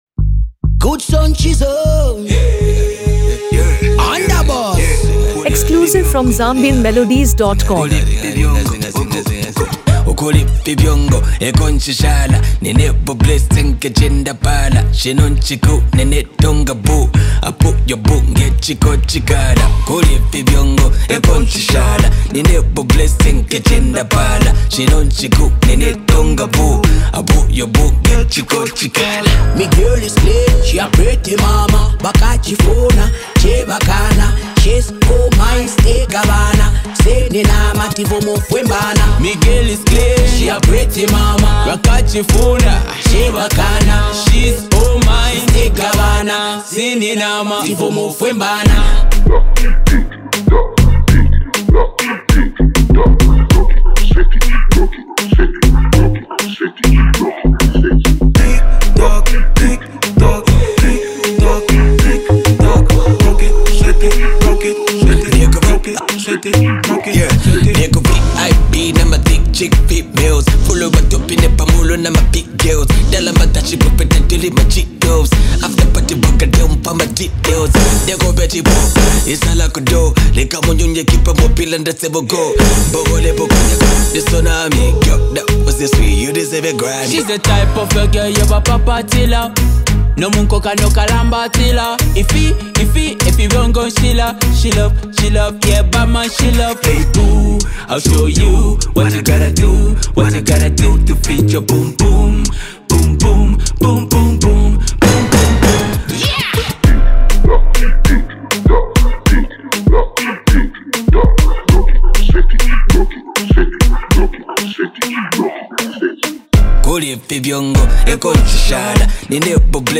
A Powerful Afro-Dancehall Anthem